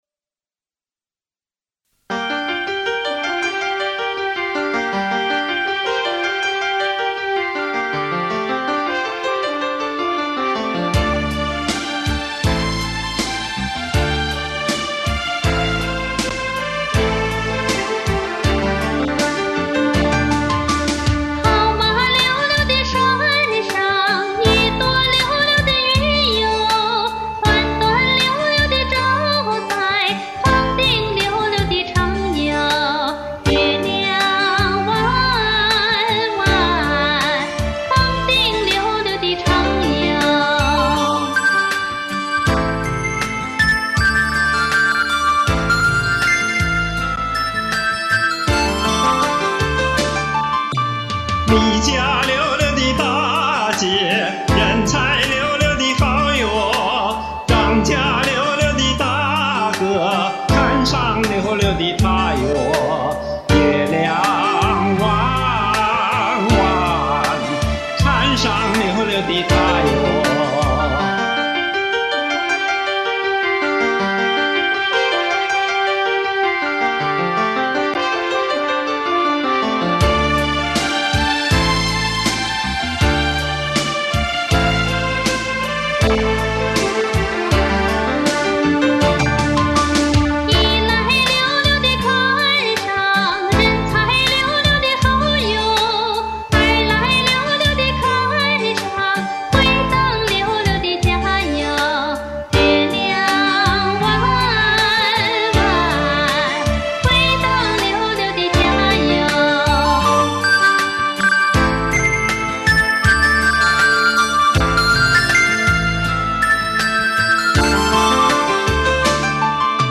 kdqg_duet.mp3